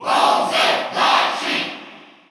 Category: Crowd cheers (SSBU) You cannot overwrite this file.
Bowser_Jr._Cheer_Russian_SSBU.ogg.mp3